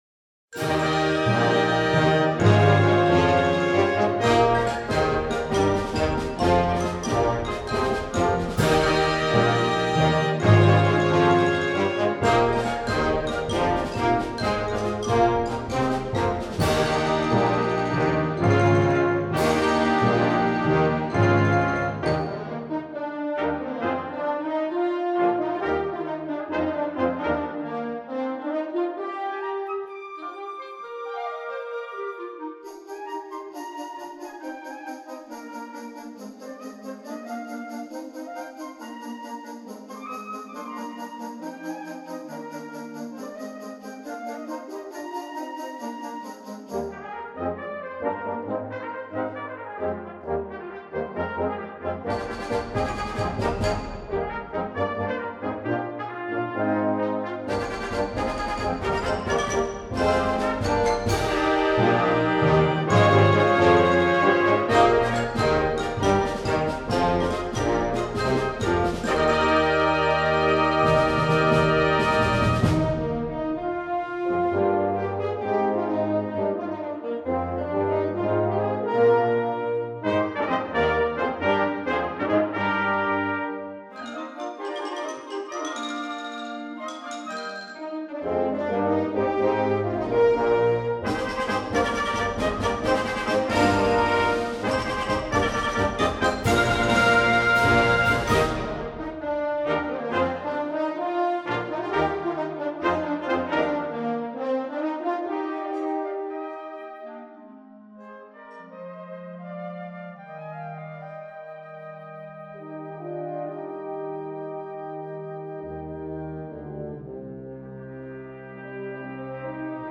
Instrumentation: concert band
instructional